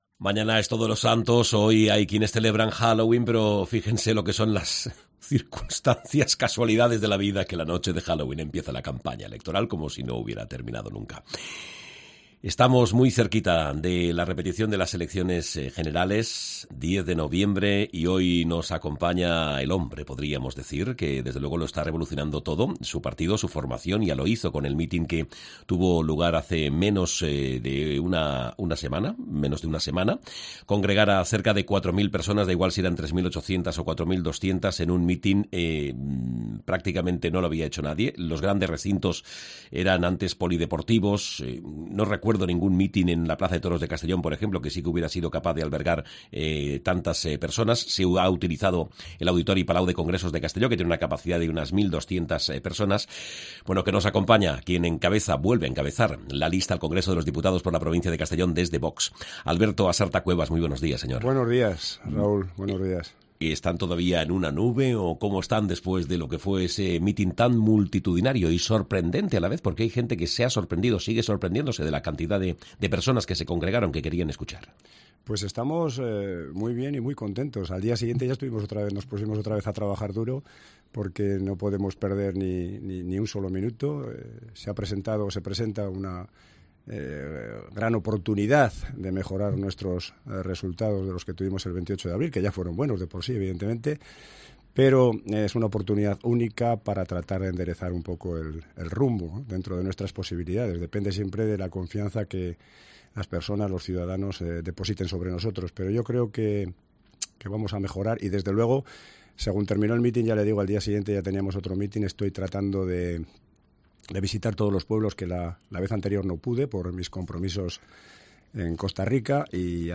Entrevista a Alberto Asarta, candidato de VOX al Congreso de los Diputados por Castellón